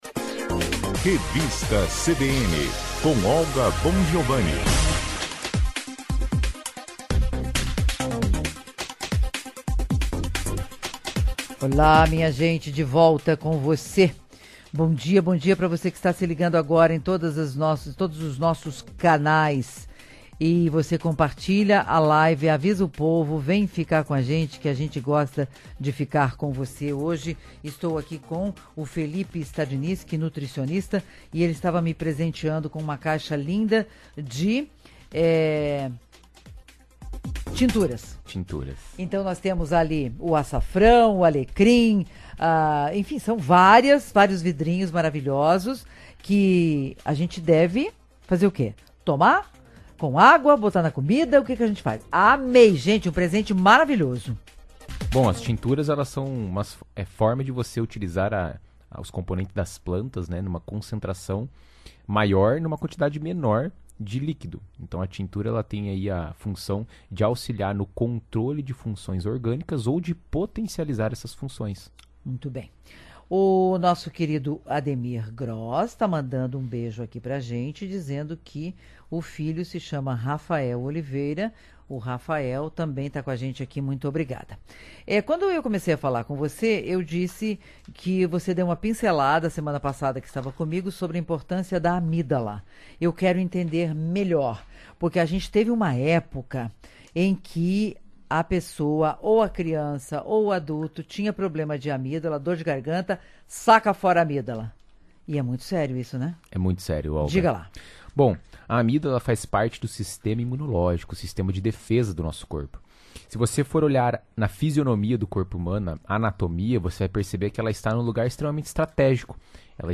em entrevista à Revista CBN fala sobre a relação da comida e das emoções.